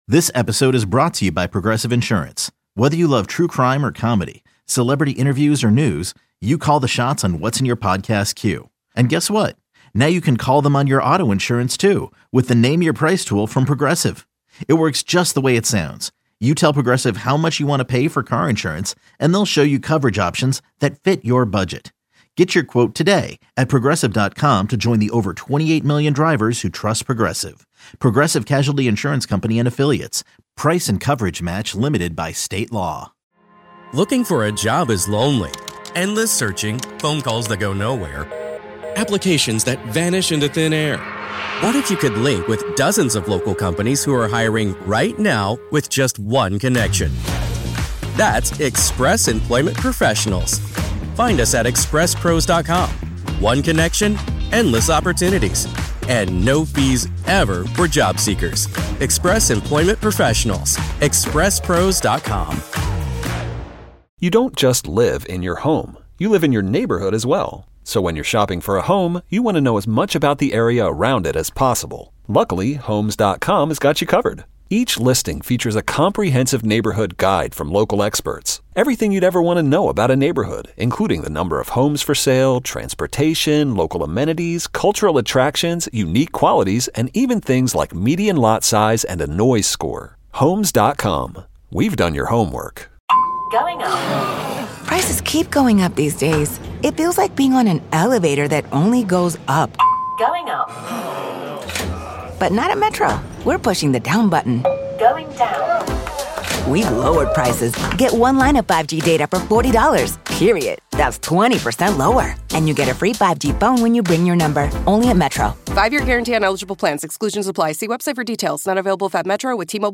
Paul Feig In Studio.
Greg Grunberg In Studio.